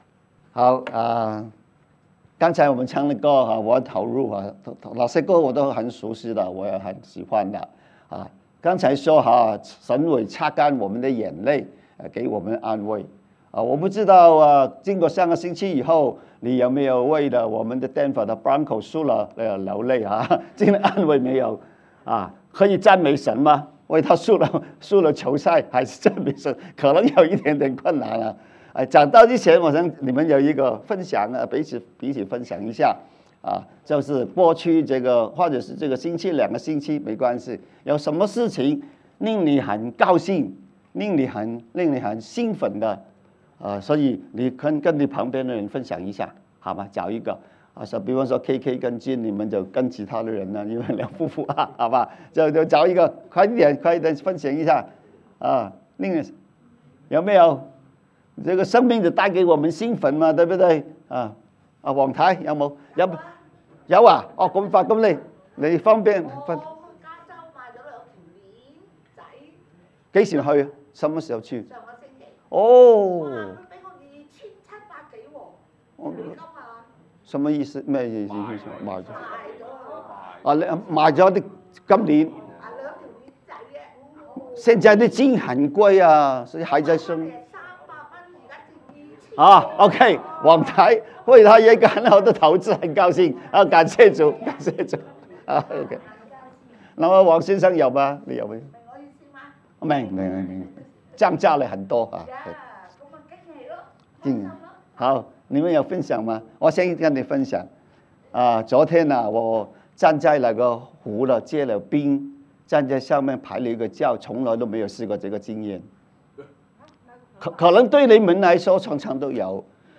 主頁 Home 關於我們 About Us 小組 Small Groups 事工 Ministry 活動 Events 主日信息 Sermons 奉獻 Give 資源 Resources 聯絡我們 Contact 為主受苦的士每拿教會